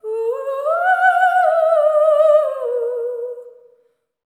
LEGATO 08 -L.wav